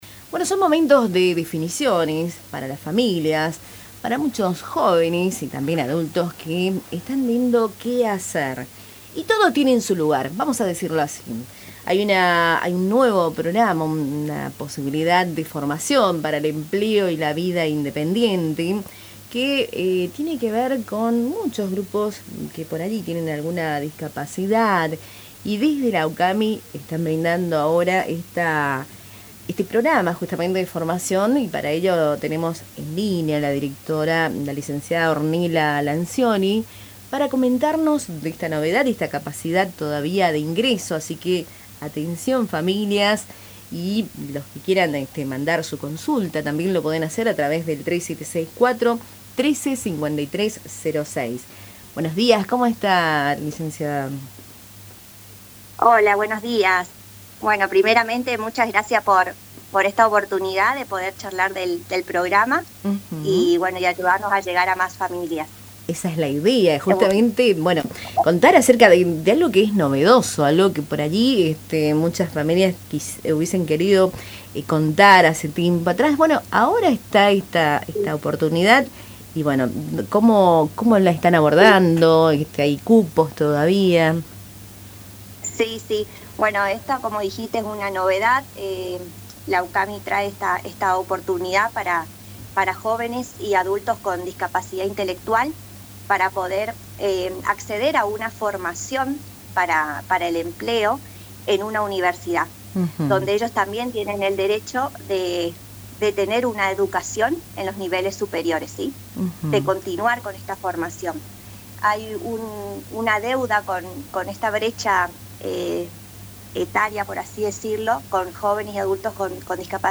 en comunicación con Cultura en Diálogo por Radio Tupambaé